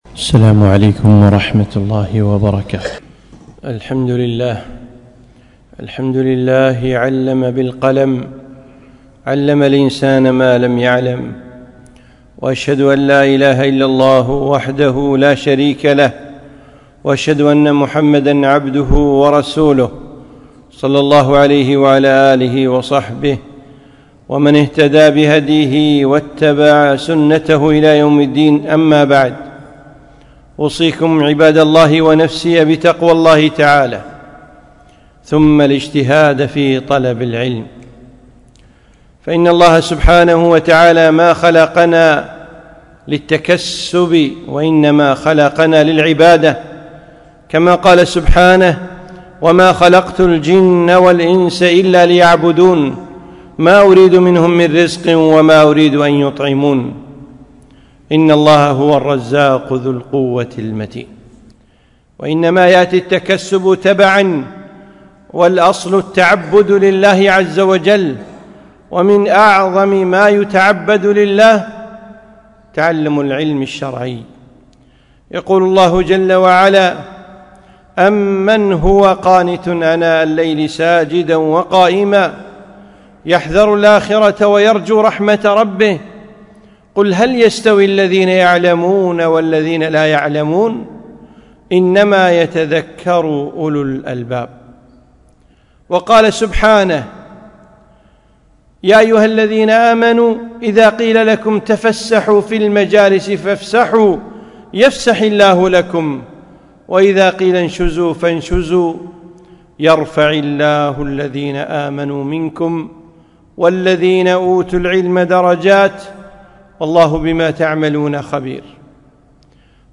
خطبة - فضل العلم